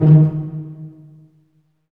Index of /90_sSampleCDs/Roland LCDP13 String Sections/STR_Vcs Marc&Piz/STR_Vcs Pz.2 amb
STR PIZZ.0BR.wav